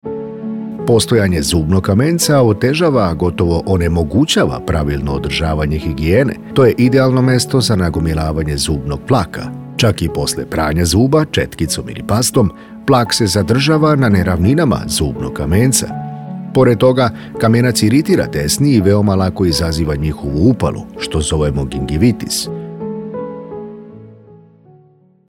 Primer zabavna dinamična glasovna varijanta za reklame ili najave
Izbiljan voice, sa autoritetom, koji objasnjava u videu ili reklami
Ovo su primeri kako na kraju zvuci instagram reklama kada se snimi profesionalni glas po finalnom tekstu, a kasnije video sami spajate prema glasu.
tik-tok-instagram-video-voiceover-snimanje-i-glas.mp3